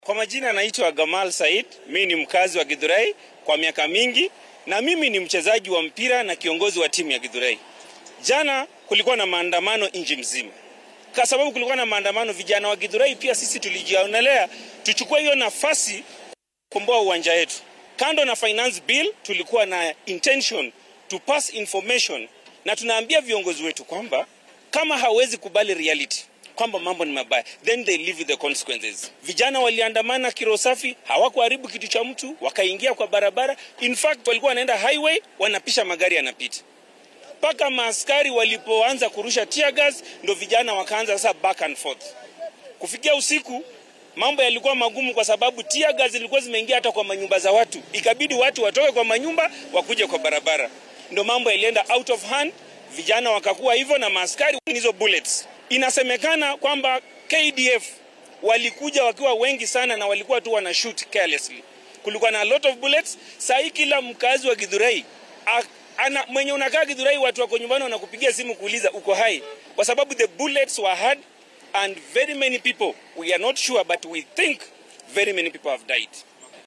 Iyadoo xalay la soo tabiyay in dad lagu dilay xaafadda Githurai ayaa waxaa warbaahinta la hadlay mid ka mid ah dadweynaha halkaasi ku nool oo faahfaahinaya sida ay wax u dhaceen.